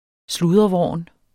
Udtale [ ˈsluðʁʌˌvɒˀn ]